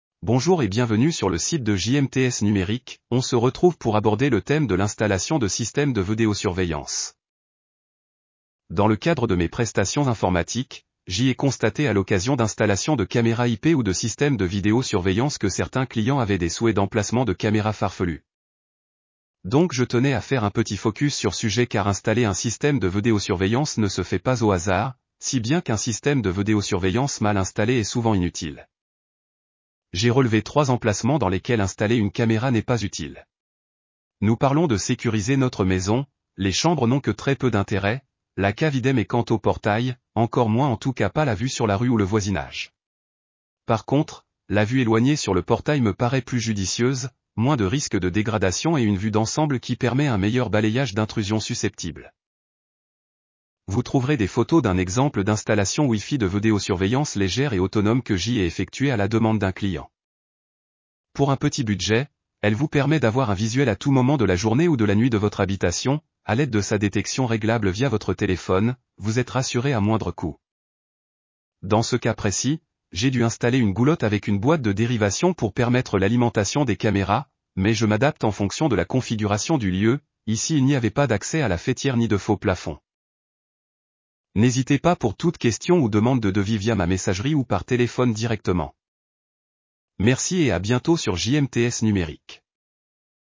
Pour écouter cet article avec l’ I.A.